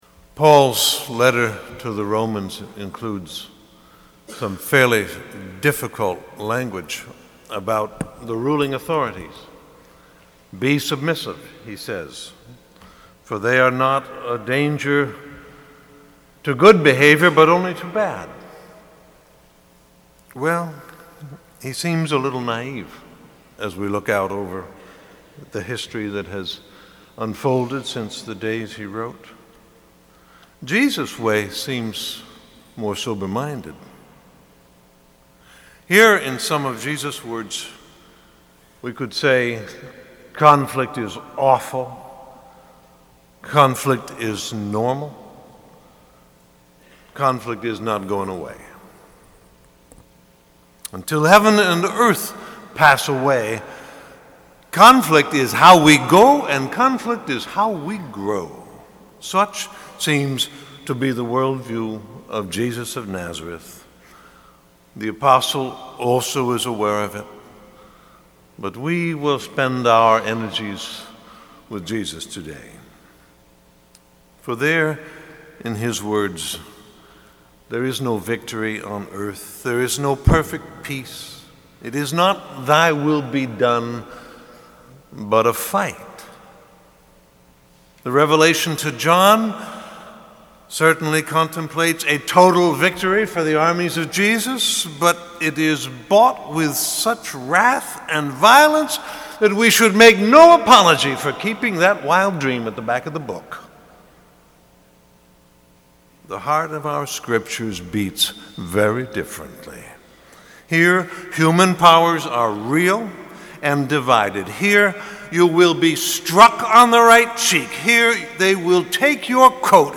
sermon 2012